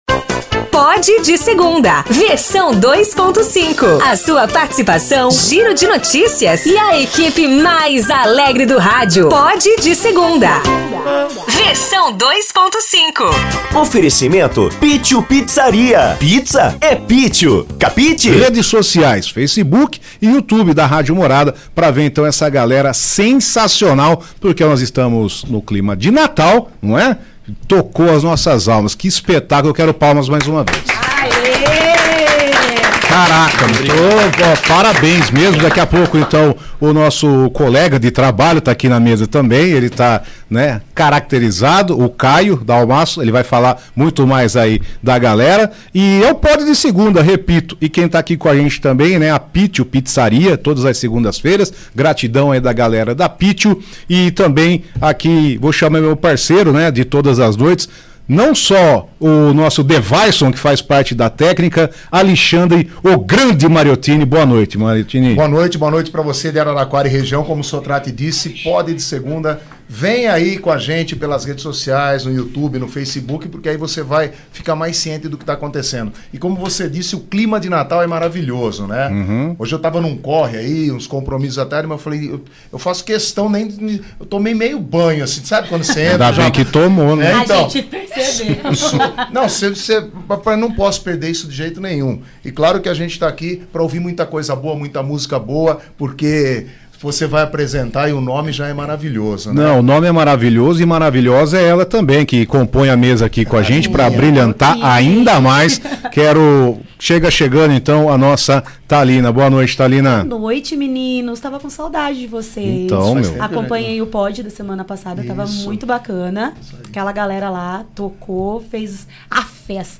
Um papo descontraído, muita música e histórias de quem vive o mundo sertanejo!